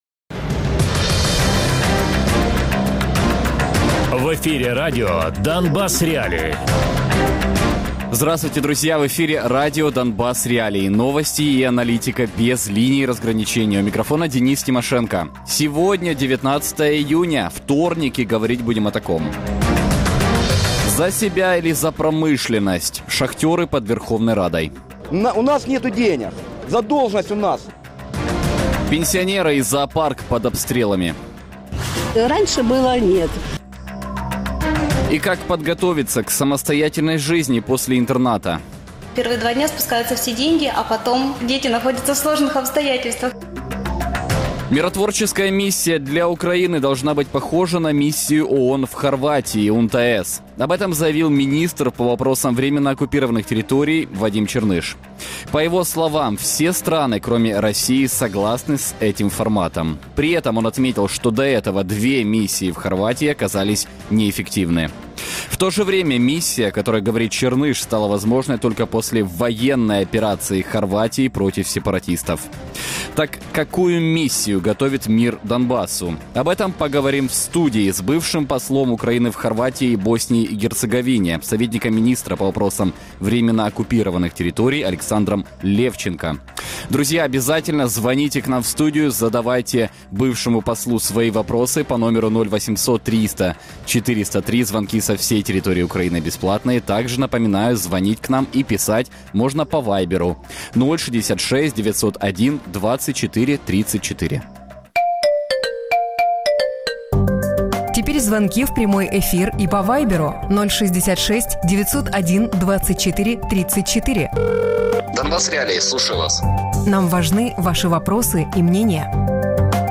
Гість: Олександр Левченко - колишній посол України в Хорватії (2010-2017) і Боснії і Герцеговині (2011-2017), радник міністра з питань тимчасово окупованих територій Радіопрограма «Донбас.Реалії» - у будні з 17:00 до 18:00. Без агресії і перебільшення. 60 хвилин про найважливіше для Донецької і Луганської областей.